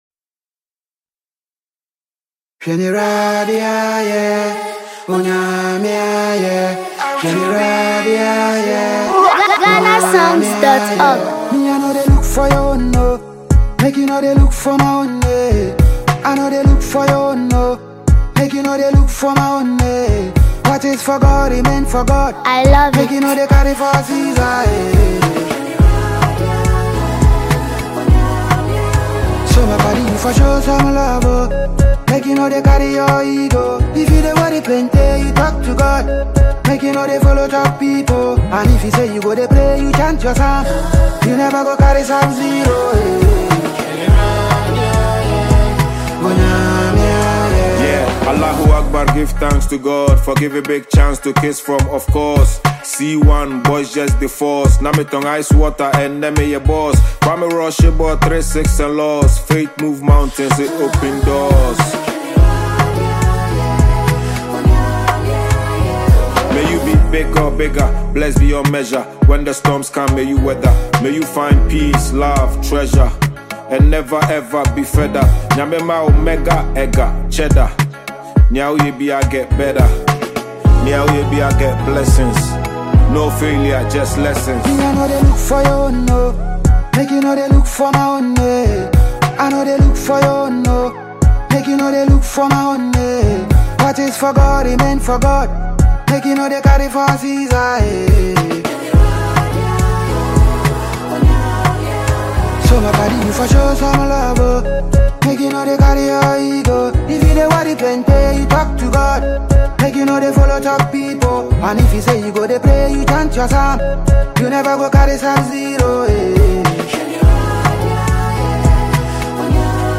Award-winning Ghanaian music duo
a soulful tune